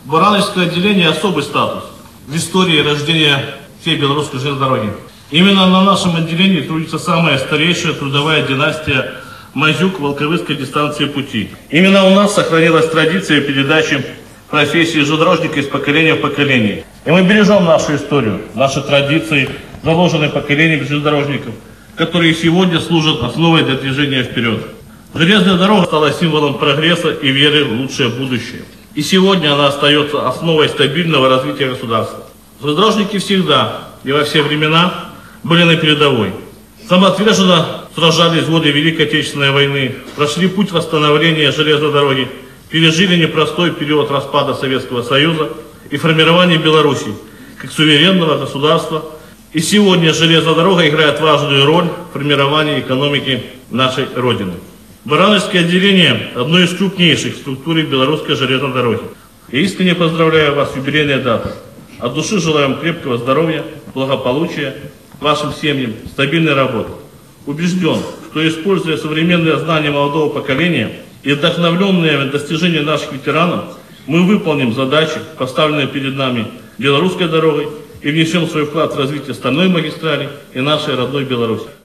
Торжественное мероприятие, посвященное 160-летию Белорусской железной дороги, состоялось в Барановичах в городском Доме культуры.